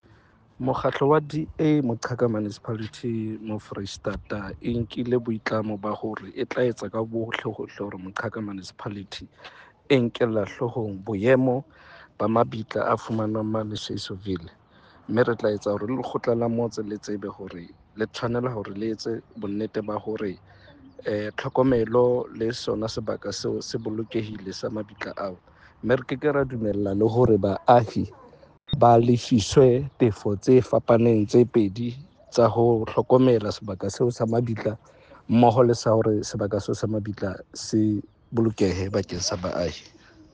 Sesotho soundbites by Cllr Dennis Khasudi.